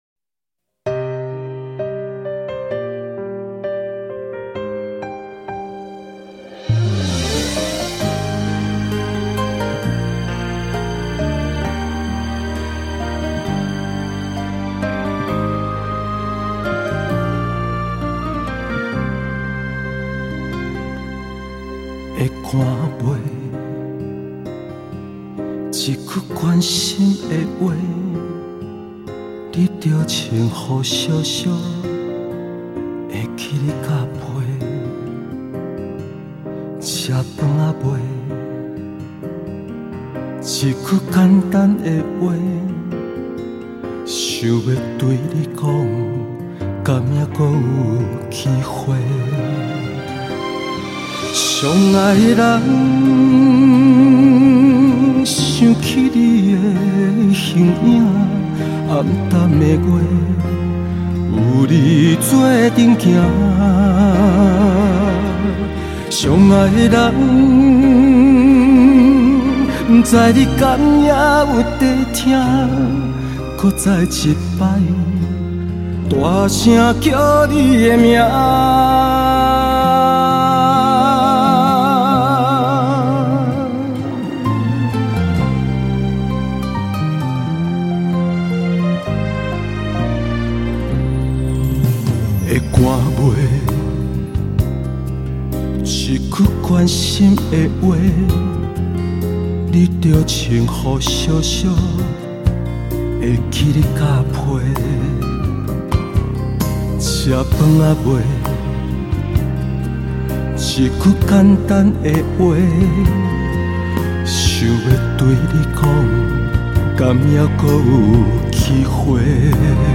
【音樂類型】：華語台語